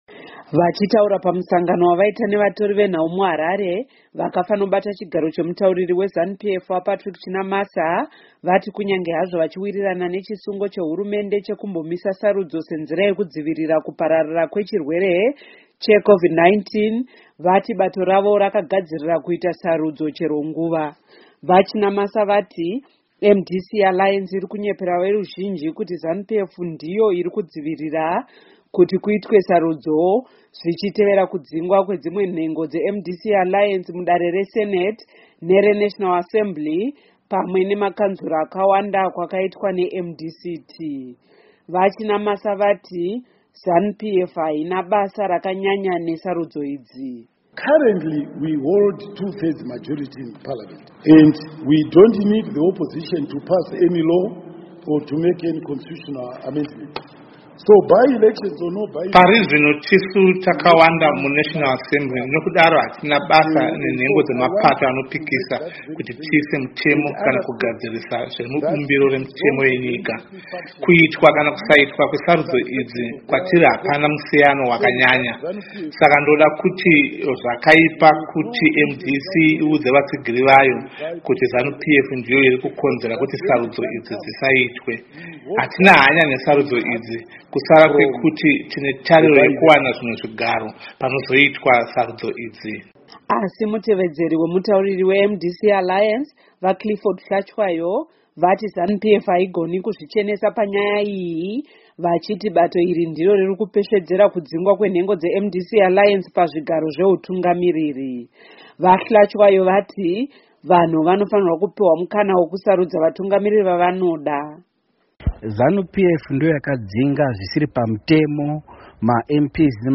Zanu PF press conference